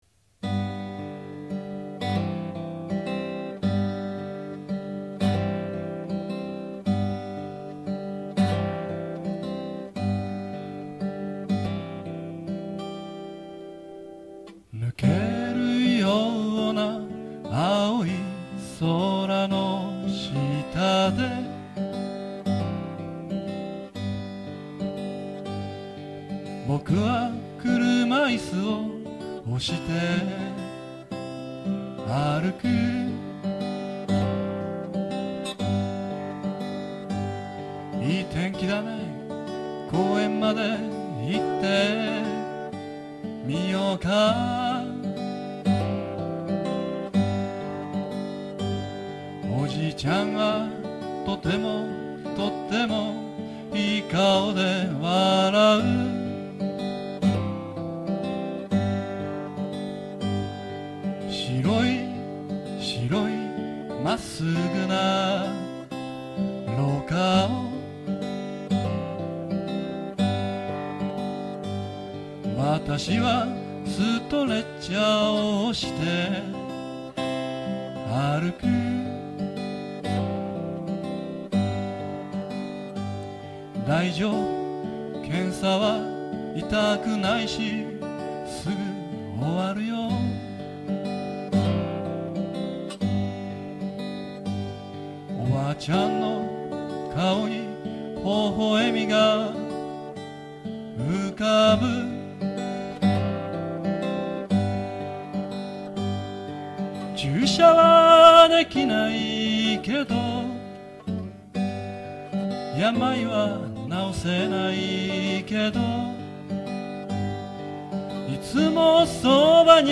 確かにスリーコードの典型的な素人フォークソングです。
ただ、作った曲はやたらと地味です。